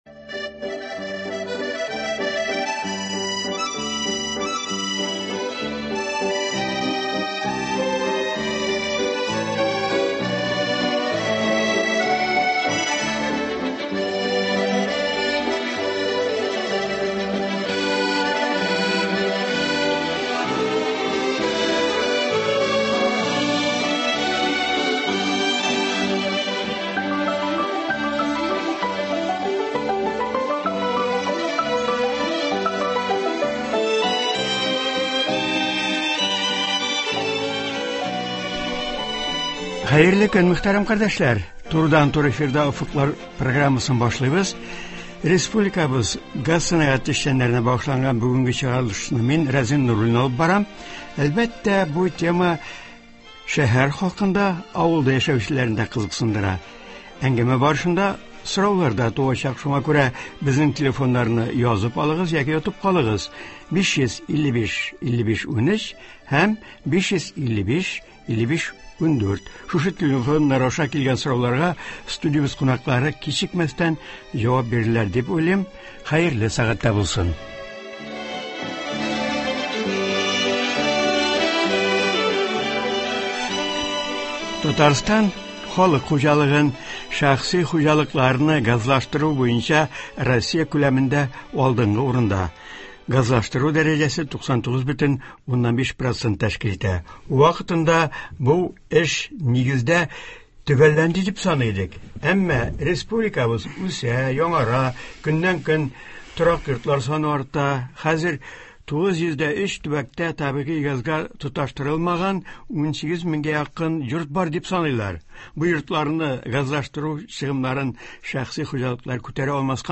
Бу эшләр Татарстанда ничек оештырыла? Болар хакында турыдан-туры эфирда